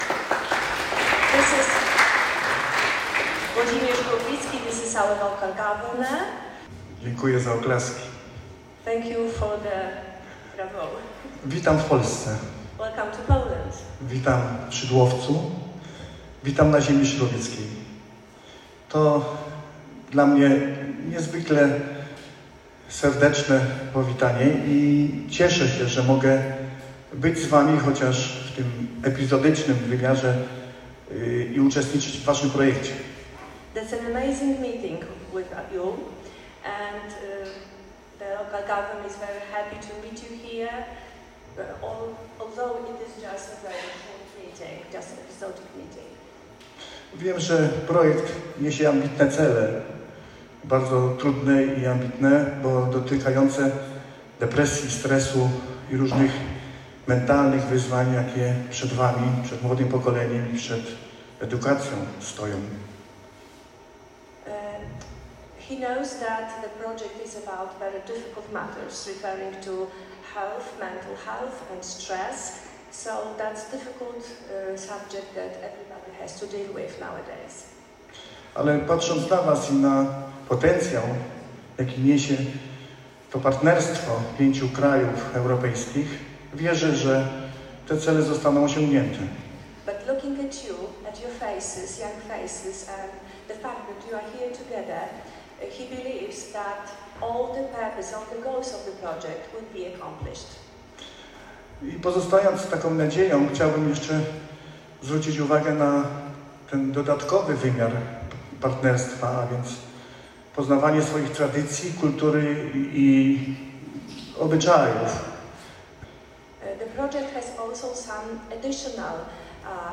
W sali konferencyjnej Starostwa Powiatowego w Szydłowcu, gości na ziemi szydłowieckiej powitał starosta Włodzimierz Górlicki, który w ciepłych słowach odniósł się do walorów jakie stwarza międzynarodowa wymiana młodzieży oraz przekazał przesłanie – bądźcie szczęśliwi na naszej ziemi i zawieźcie do swoich krajów dobrą opowieść o Polsce.
powiatanie_erasums_starosta_szydlowiecki.mp3